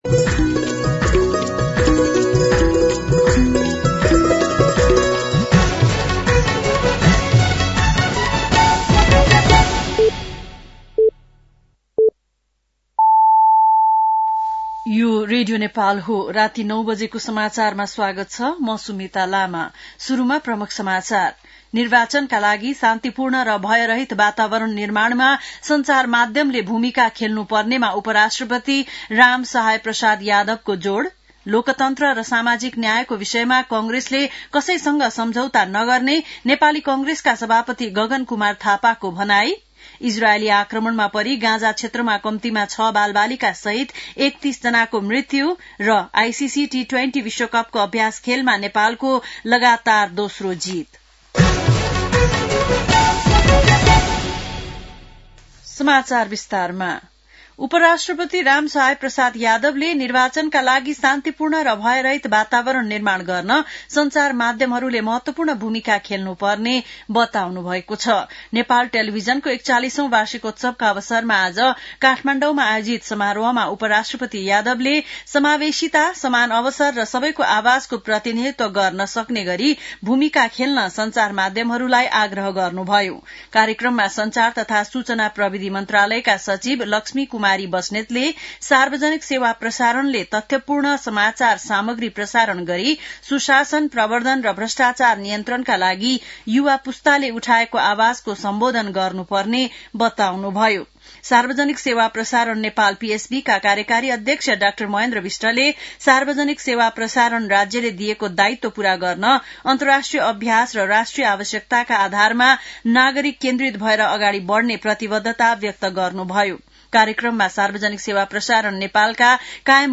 बेलुकी ९ बजेको नेपाली समाचार : १७ माघ , २०८२
9-pm-nepali-news-.mp3